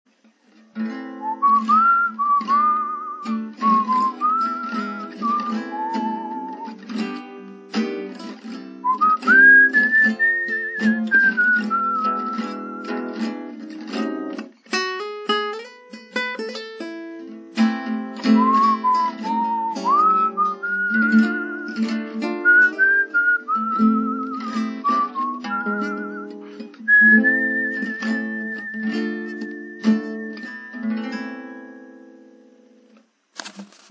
: en la mineur